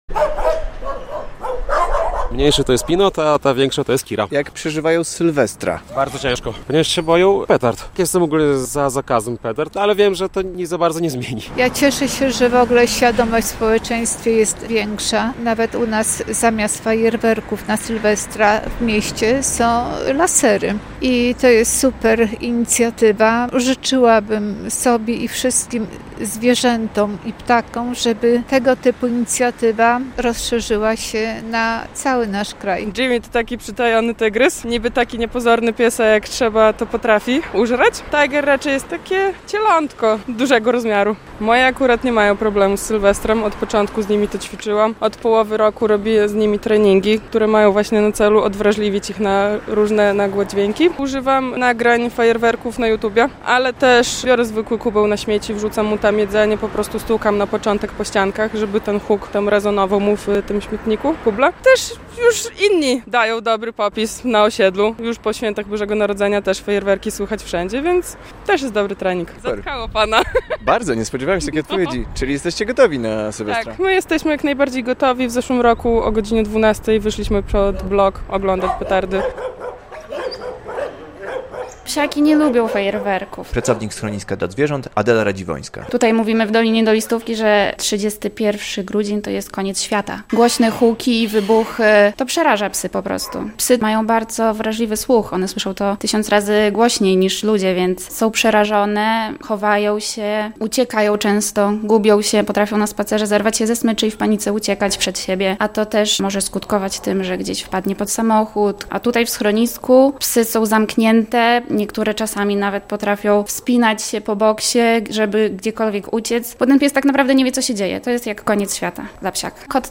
Zwierzęta boją się fajerwerków - relacja
Jak fajerwerki wpływają na zwierzęta i czy można coś z tym zrobić - pytamy białostoczan - ekspertów i właścicieli zwierząt.